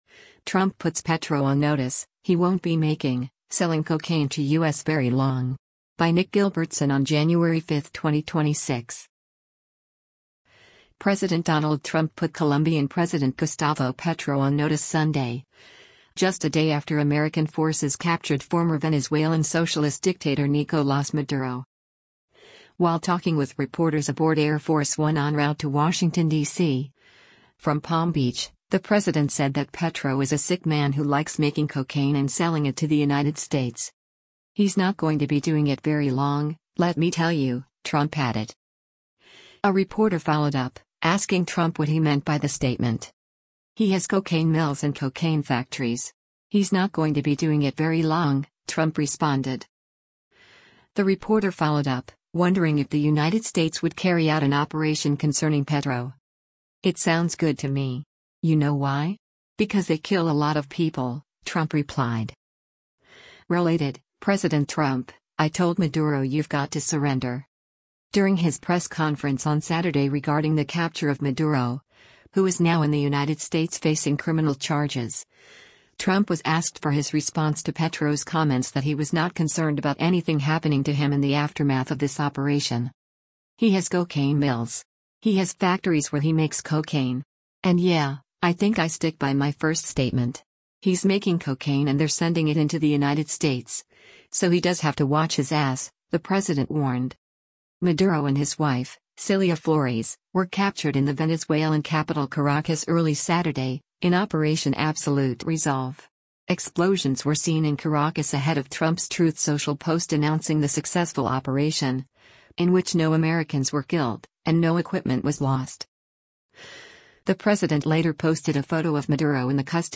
While talking with reporters aboard Air Force One en route to Washington, DC, from Palm Beach, the president said that Petro is “a sick man who likes making cocaine and selling it to the United States.”
A reporter followed up, asking Trump what he meant by the statement.